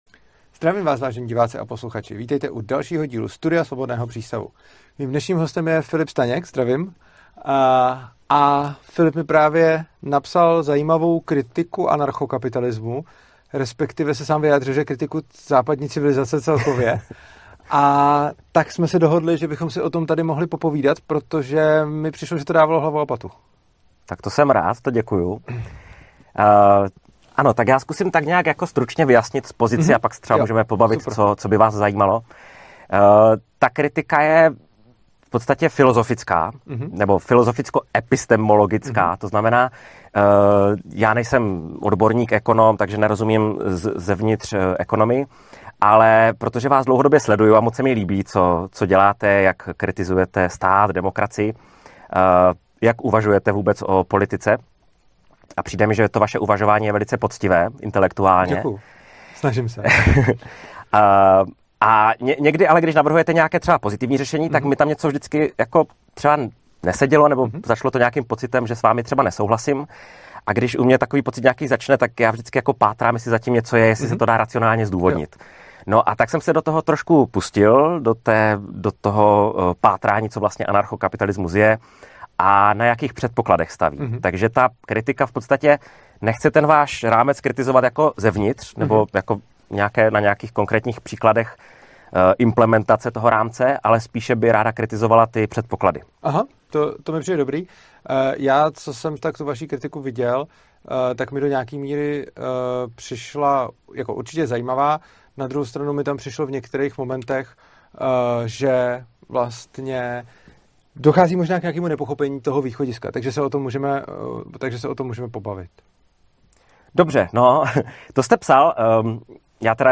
Rozhovor jsme začali benefity i hrozbami umělé inteligence; a celkem rychle jsme přešli k financování vědy. Ačkoliv Tomáš Mikolov chápe škodlivost dotací (zejména třeba v zemědělství), v určitých oborech mu připadají přínosné (či možná nezbytné).